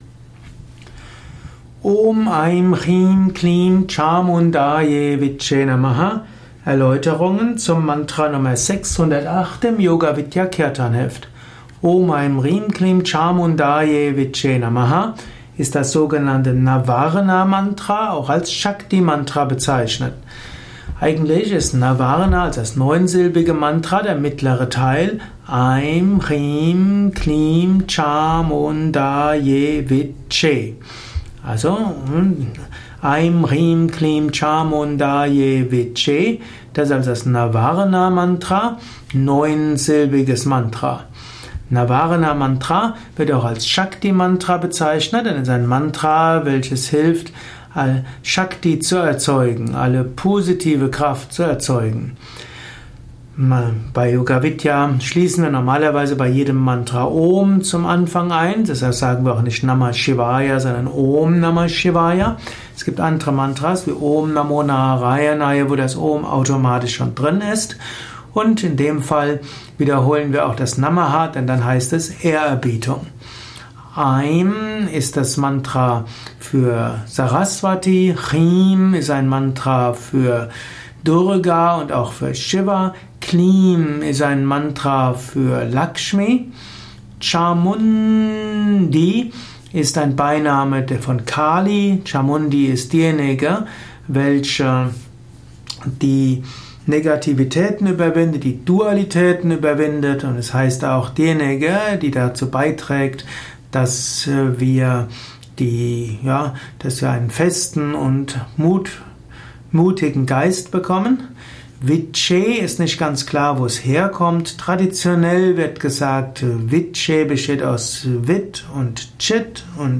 Audio mp3 Erläuterungen
Hier findest du eine Kurzvortrag